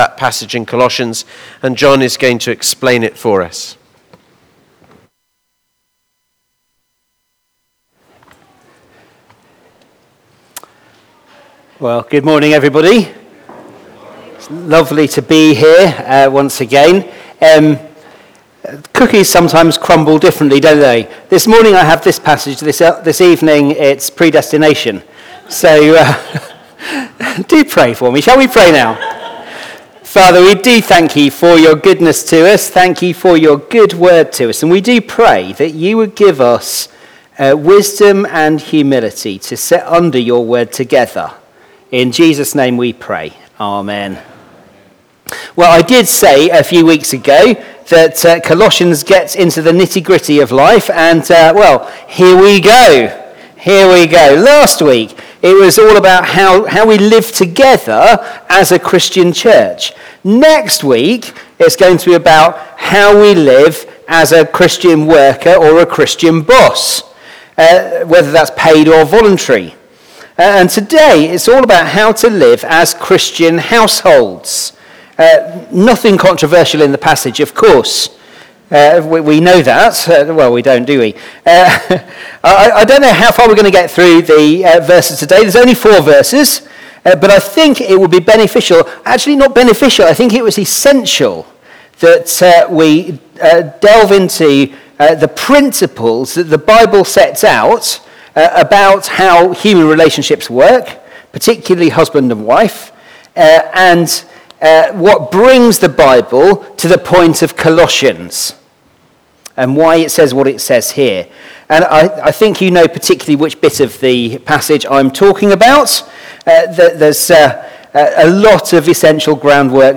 Media Library We record sermons from our Morning Prayer, Holy Communion and Evening services, which are available to stream or download below.
Media for Morning Service on Sun 15th Jun 2025 10:45 Speaker